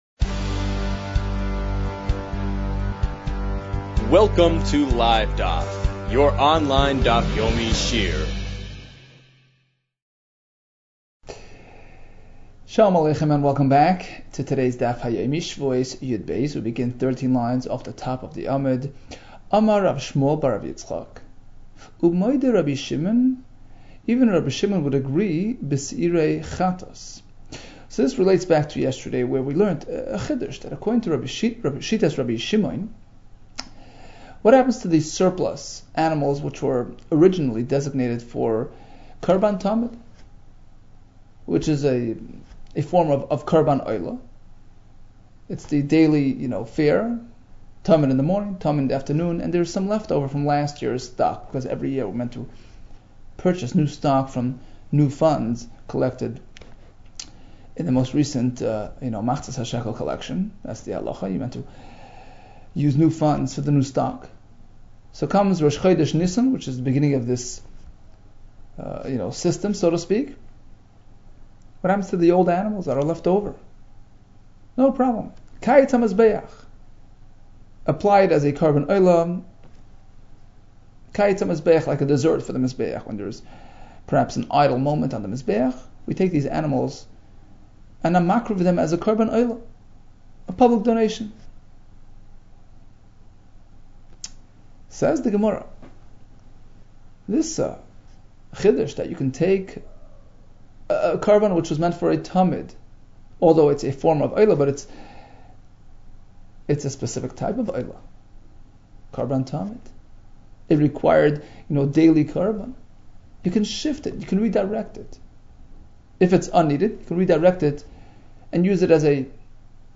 Shevuos 12 - שבועות יב | Daf Yomi Online Shiur | Livedaf